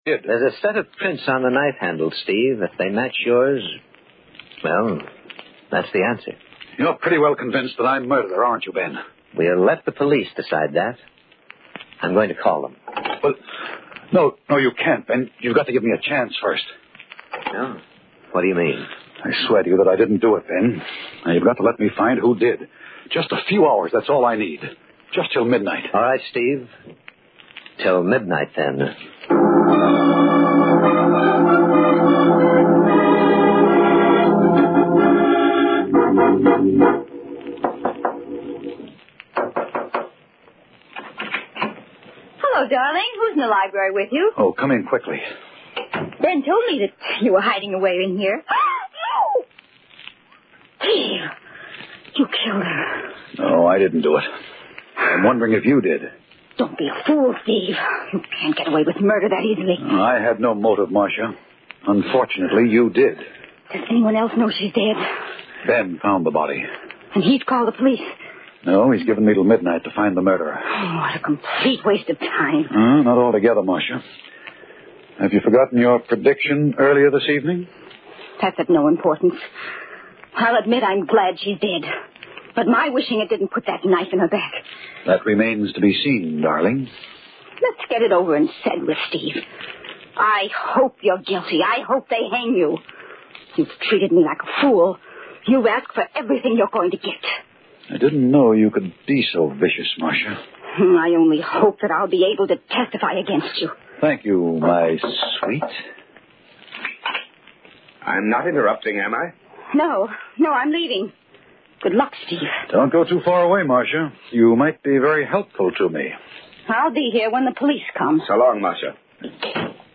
Tags: Radio Horror Mystery Radio Show The Haunting Hour